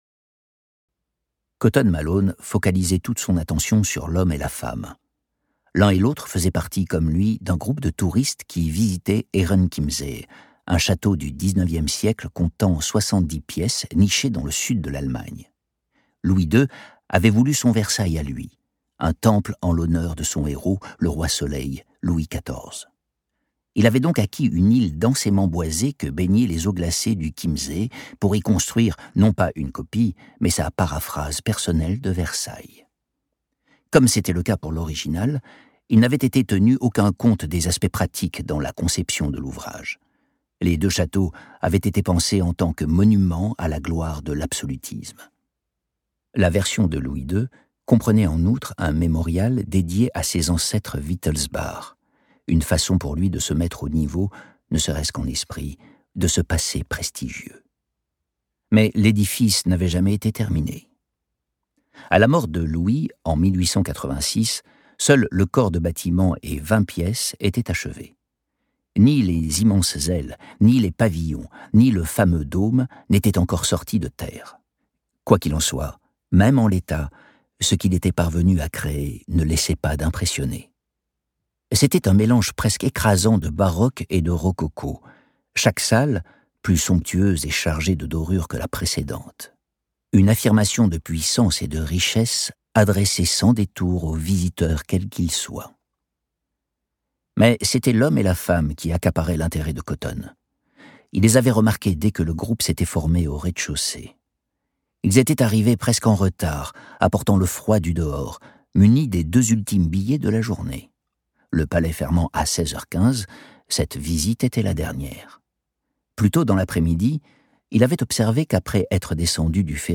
Télécharger le fichier Extrait MP3